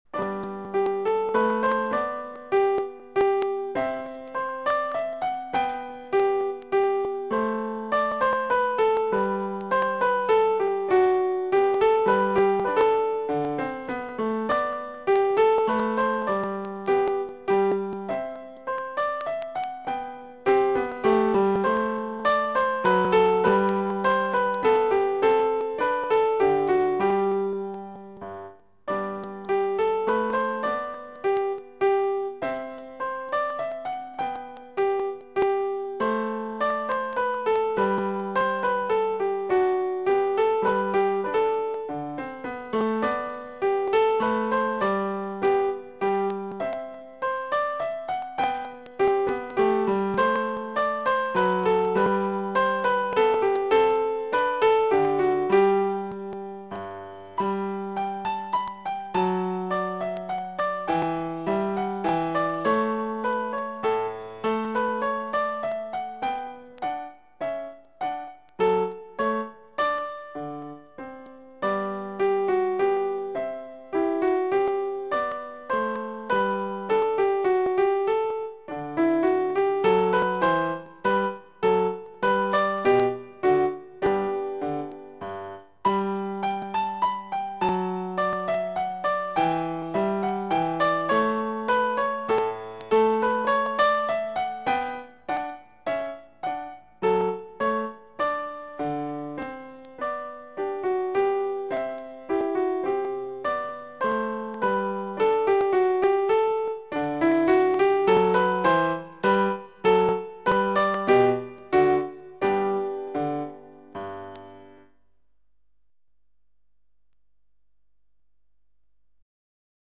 0358-巴赫小步舞曲伴奏.mp3